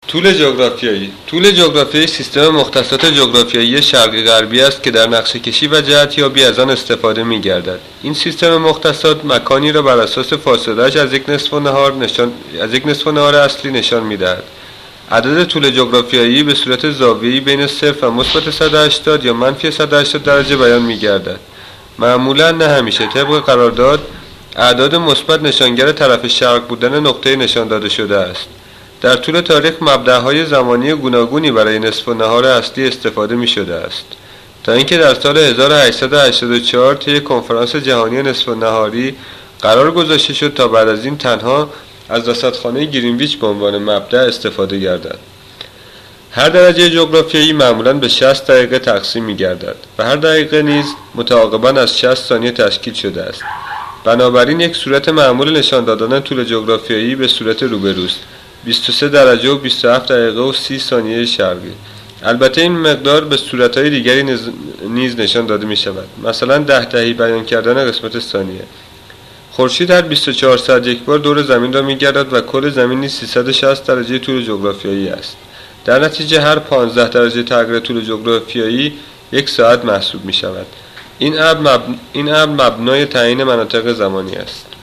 reading of a long article  from Wikipedia.